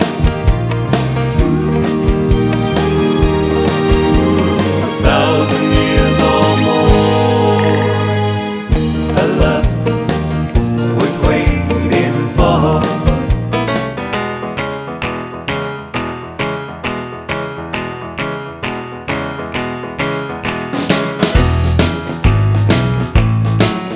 End Cut Down Christmas 2:45 Buy £1.50